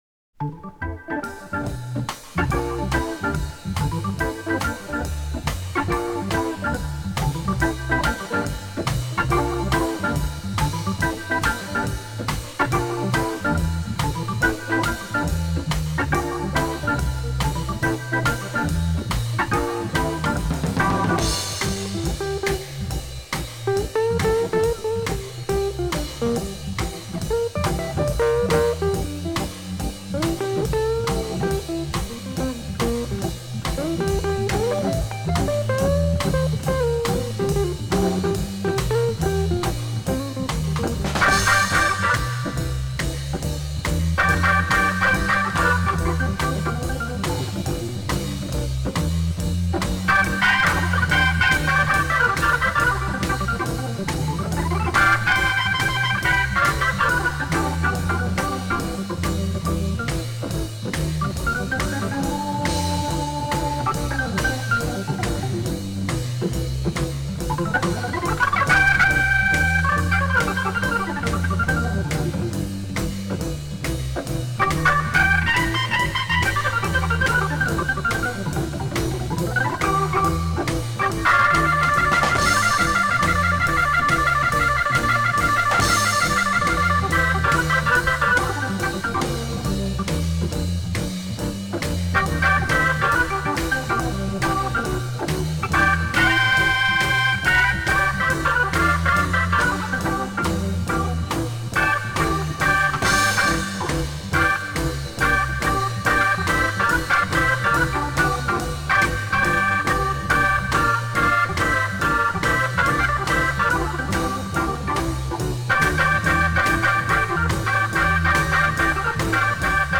Dig the upbeat vibe of